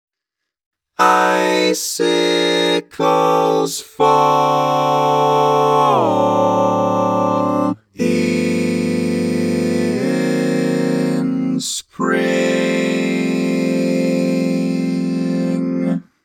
Key written in: F Major
How many parts: 4
Type: Barbershop
All Parts mix: